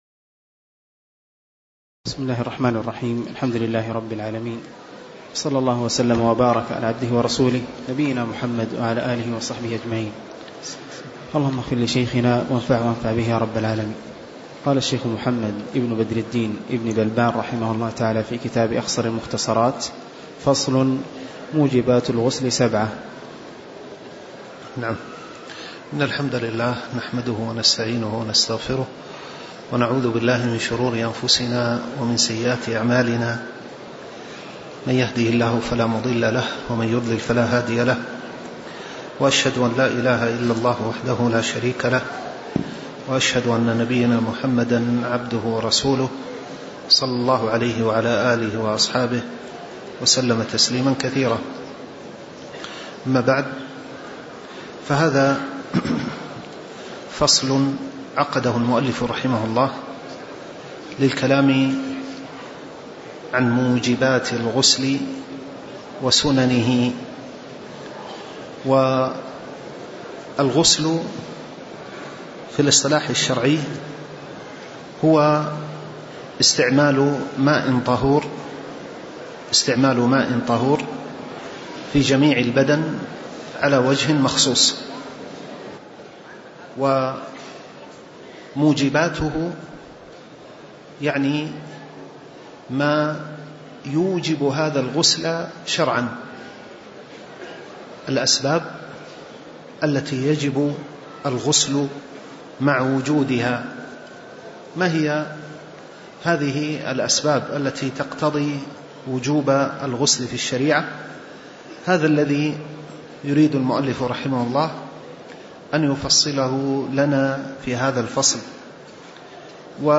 تاريخ النشر ١٠ ربيع الأول ١٤٣٩ هـ المكان: المسجد النبوي الشيخ